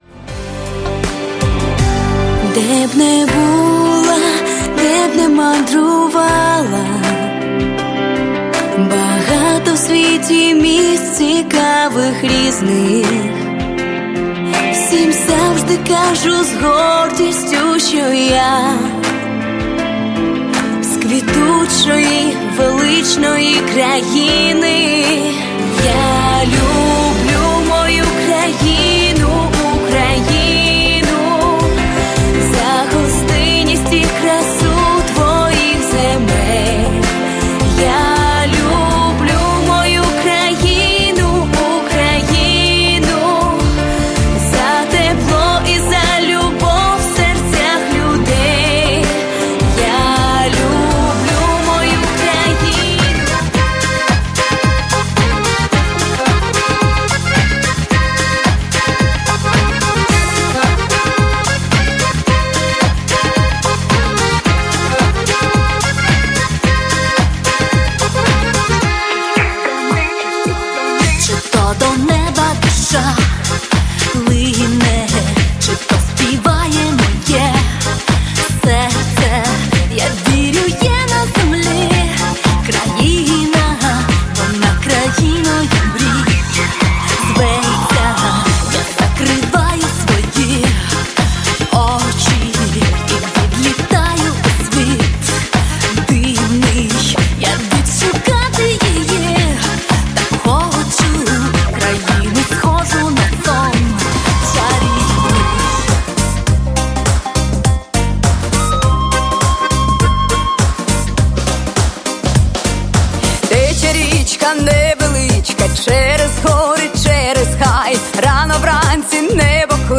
Украинская поп-музыка: